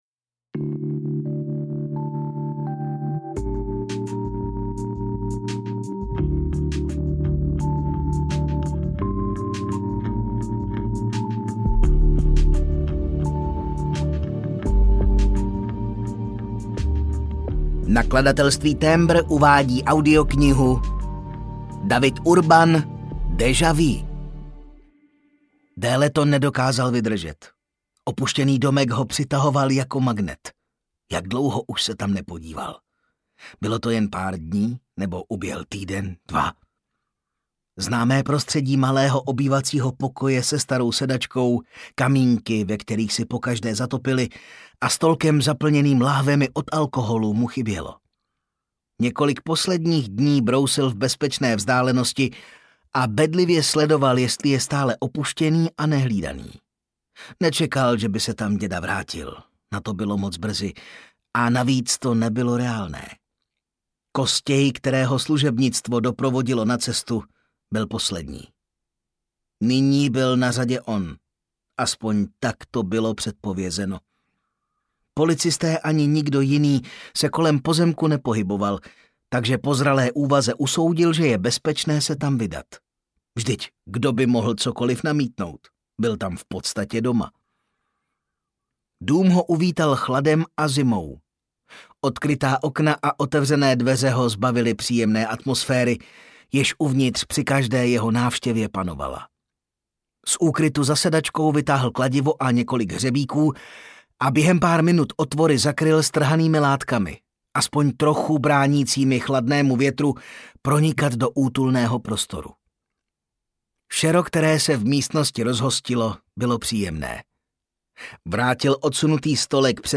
Déjà vu audiokniha
Ukázka z knihy